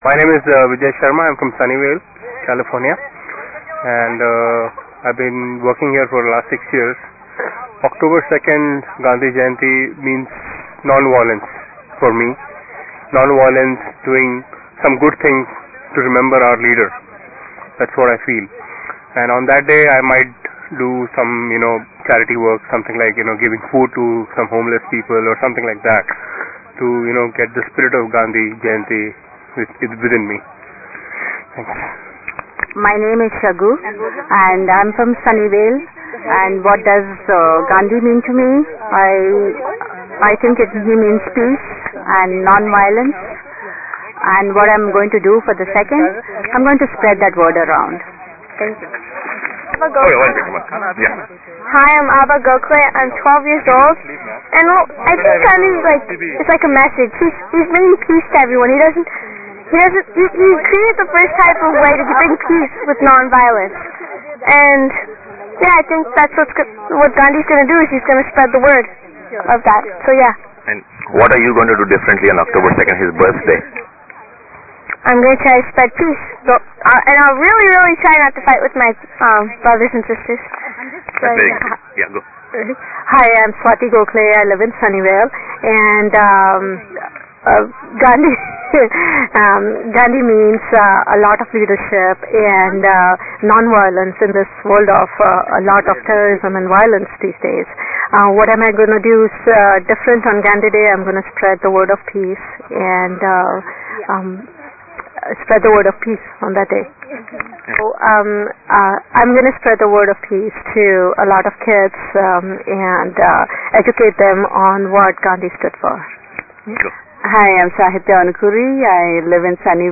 MP3 Interviews
Interview- GROUP